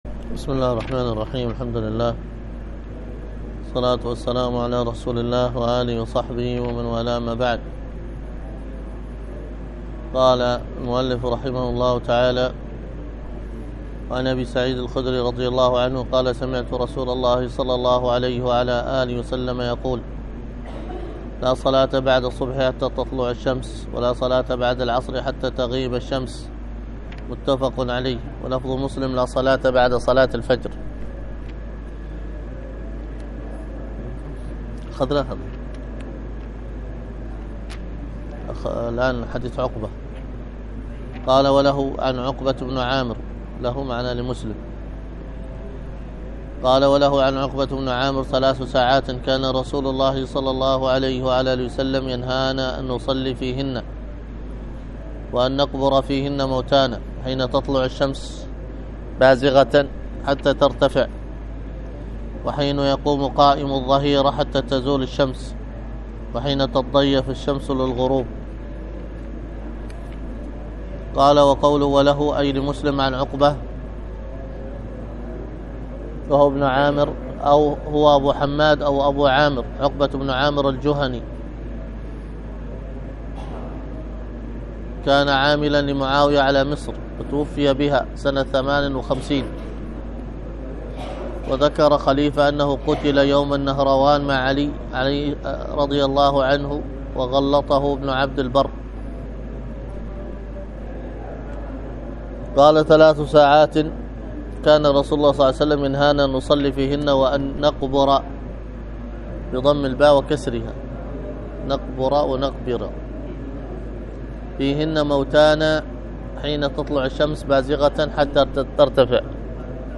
الدرس في كتاب المنثورات والملح 2